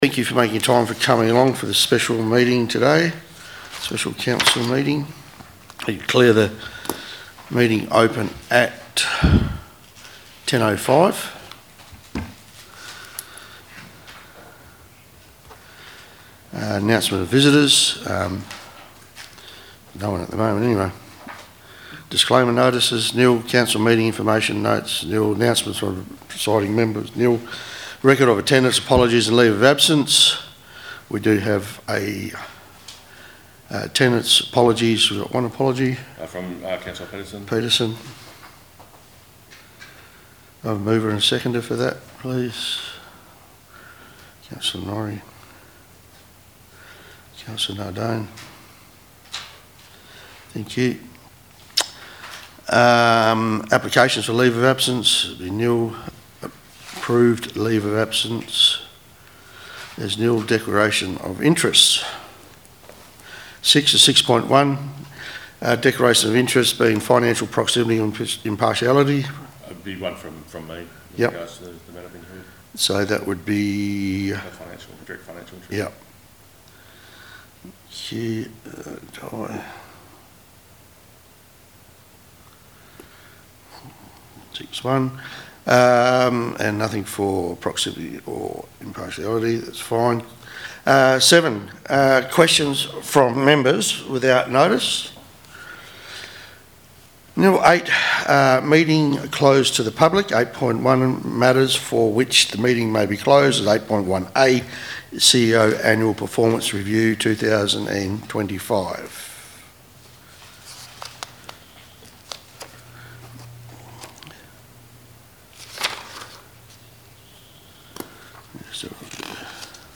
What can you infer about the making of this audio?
Special Council Meeting - 14th May 2025 » Shire of Leonora